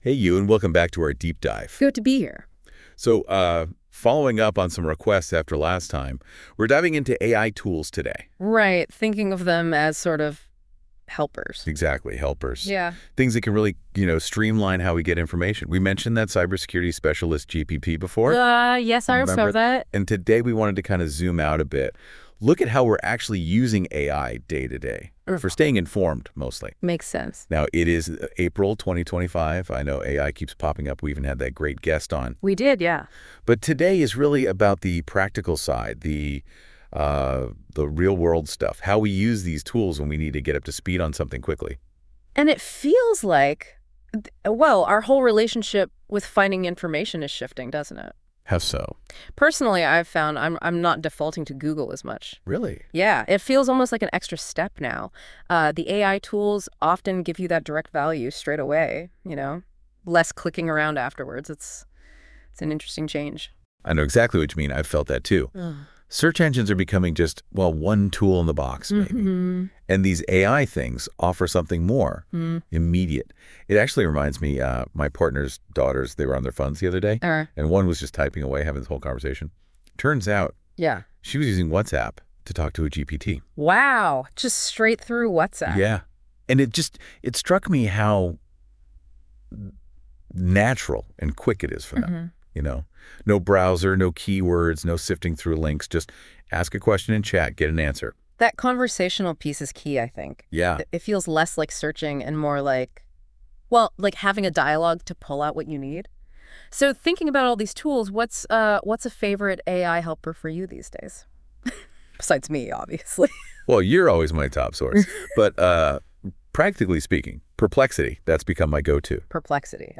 After receiving numerous inquiries about offering our podcast in English, we’ve decided to provide an AI-generated audio summary of each episode moving forward.